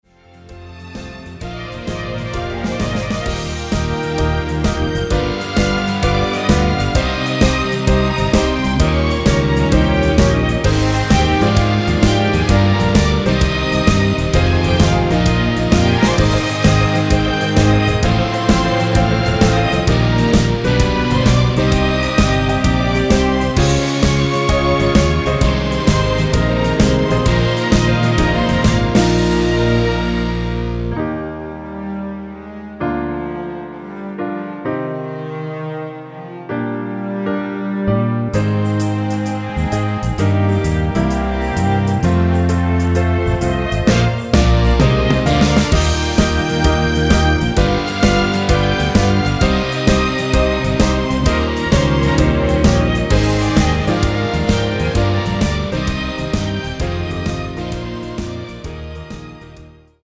전주 없는 곡이라 전주 2마디 만들어 놓았습니다.
엔딩이 페이드 아웃이라 라이브 하시기 편하게 엔딩을 만들어 놓았습니다
1절후 2절 없이 후렴으로 진행 됩니다.(본문 가사 참조)
◈ 곡명 옆 (-1)은 반음 내림, (+1)은 반음 올림 입니다.
앞부분30초, 뒷부분30초씩 편집해서 올려 드리고 있습니다.